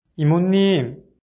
発音
이모님 [イモ二ム]